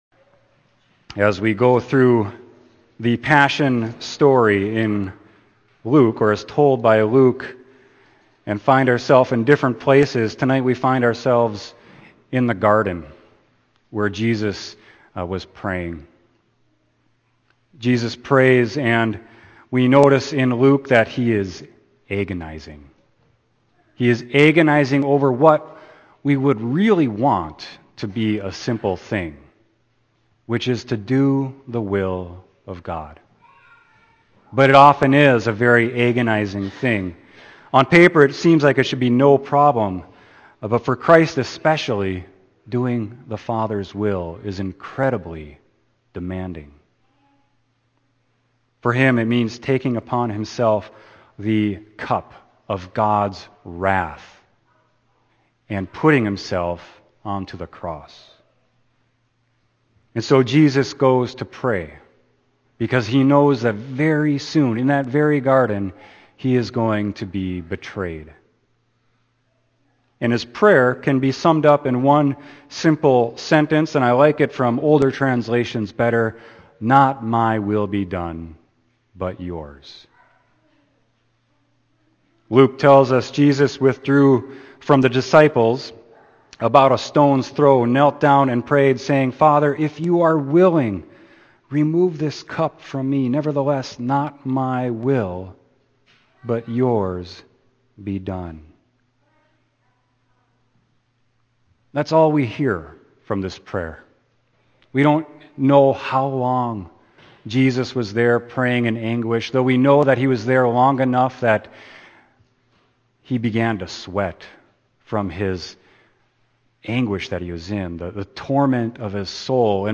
Sermon: Luke 22.39-46